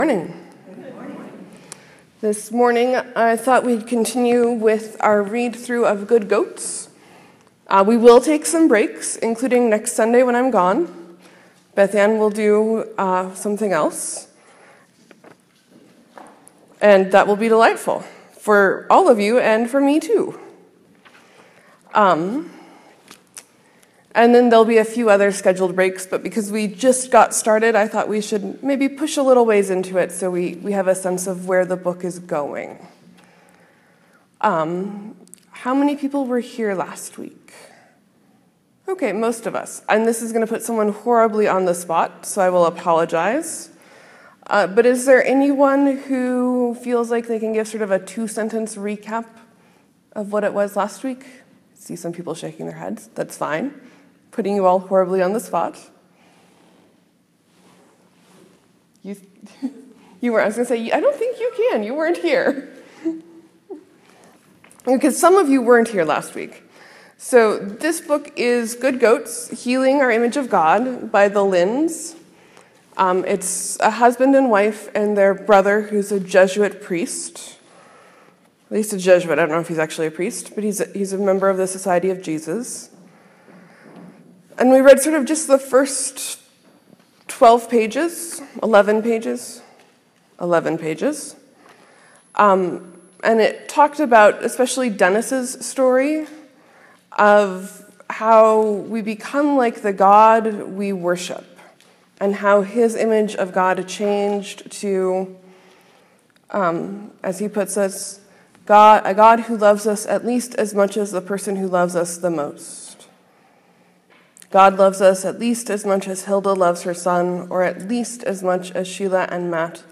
Sermon: This is a great story to read before going home. Jesus goes home and has a horrible time.